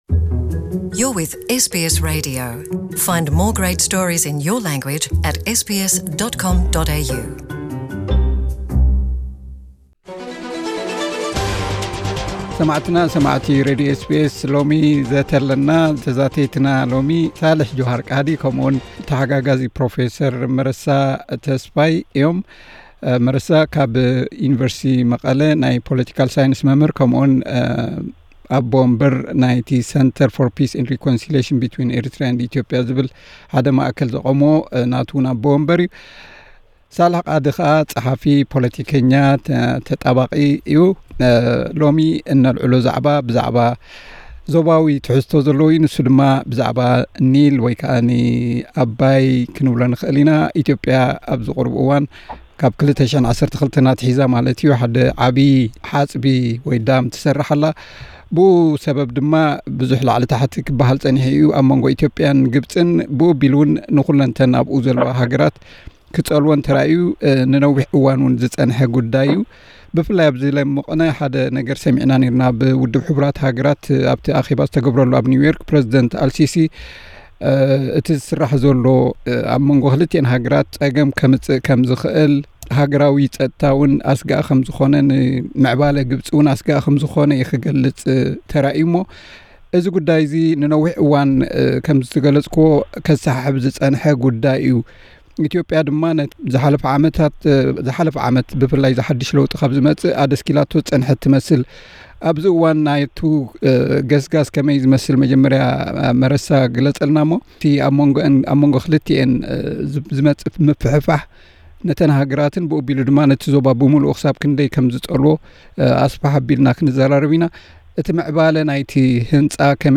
ምህናጽ ሓጽቢ ህዳሰን ኒልን ኣብ ዞባ ቀሪኒ ኣፍሪቃ ከስዕቦ ዝኽእል ፖለቲካዊ ቅልውላውን። ዘተ